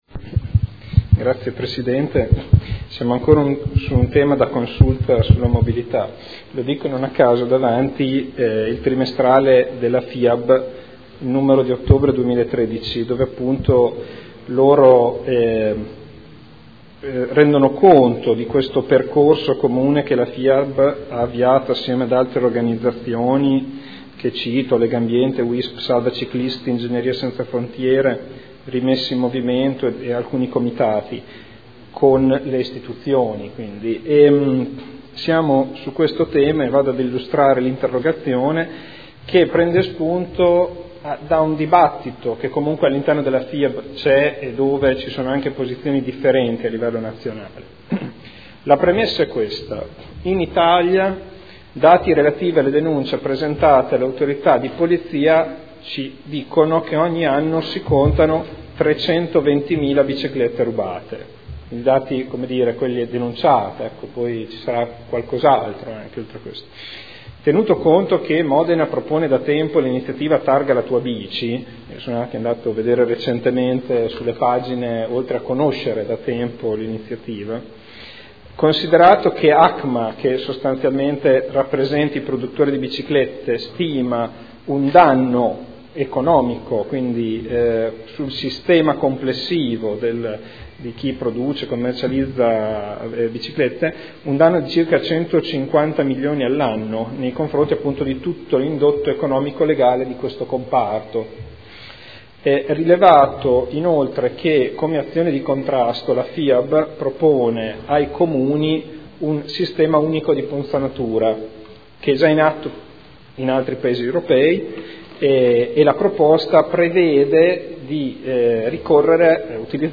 Seduta del 9 gennaio. Interrogazione del consigliere Ricci (SEL) avente per oggetto: “Punzonatura”